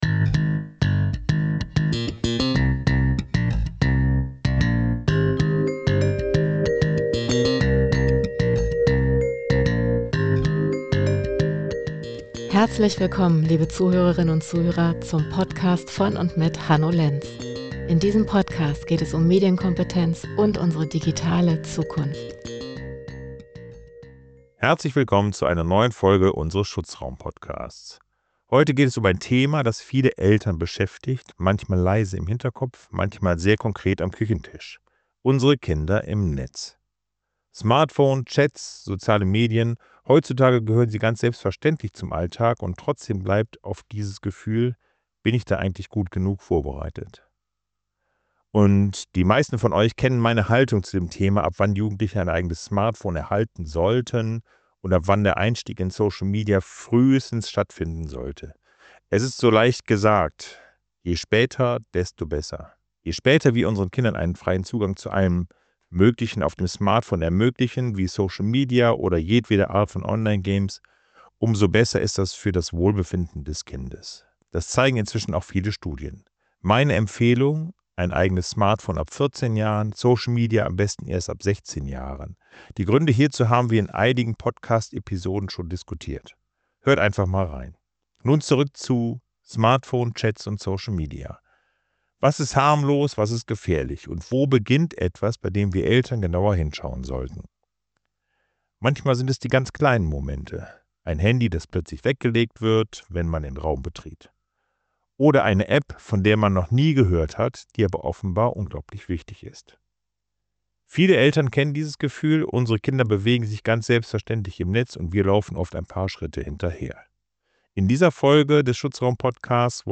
In dieser Folge des Schutzraum-Podcasts sprechen wir mit einem Staatsanwalt über genau die Punkte, bei denen digitale Alltagsnutzung plötzlich rechtlich relevant wird. Wann wird aus „Spaß“ strafbares Verhalten?